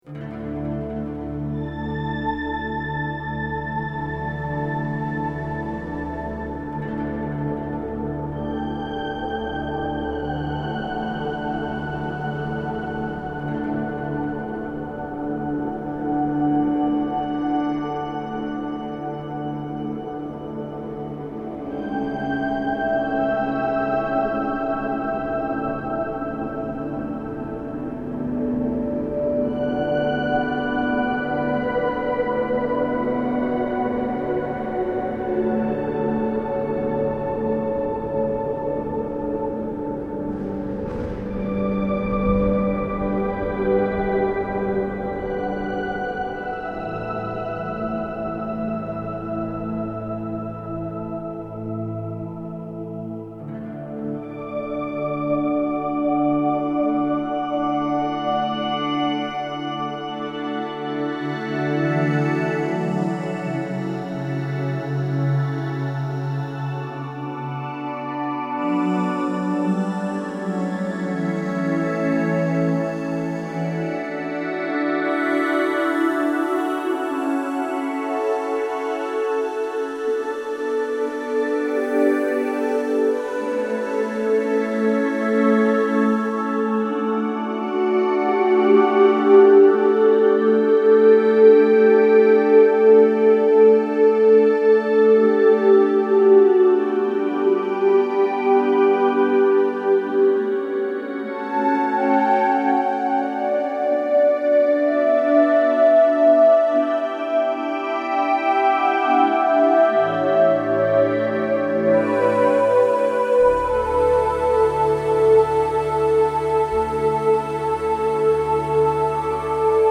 4-hang-on-meditation-song.mp3